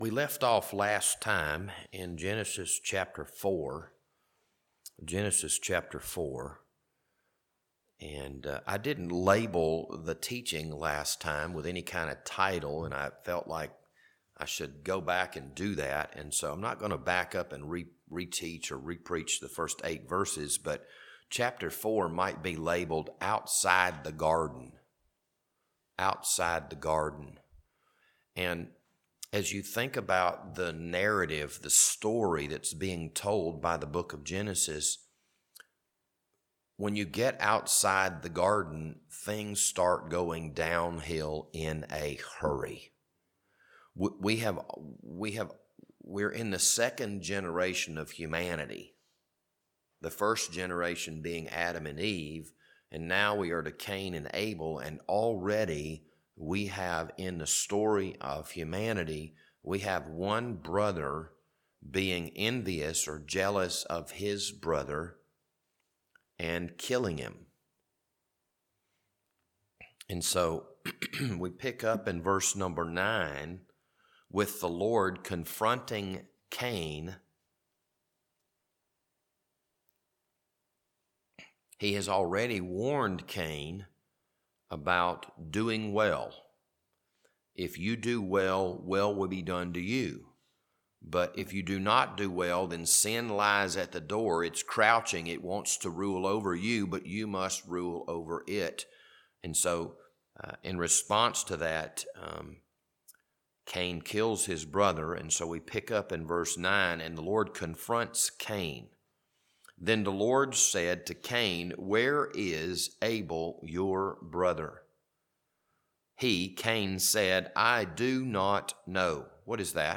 This Wednesday evening Bible study was recorded on May 18th, 2022.